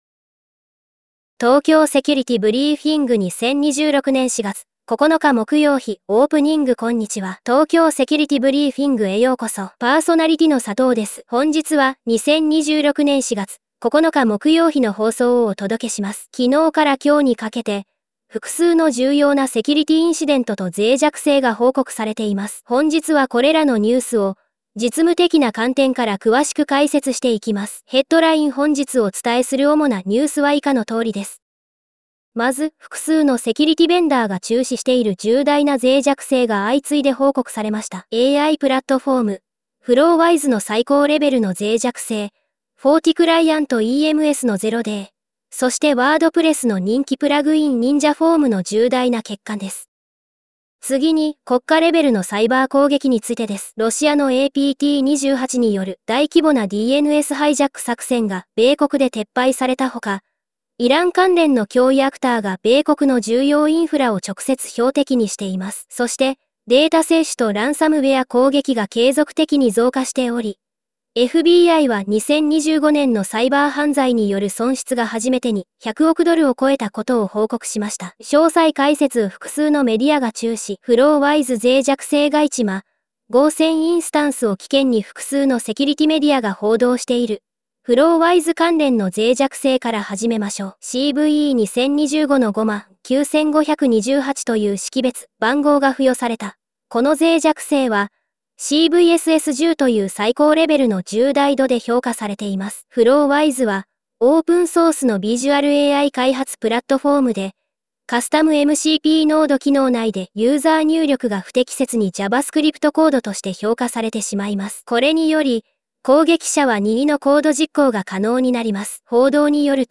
再生時間: 18:05 ファイルサイズ: 16.6 MB MP3をダウンロード トークスクリプト 東京セキュリティブリーフィング 2026年04月09日（木曜日） オープニング こんにちは。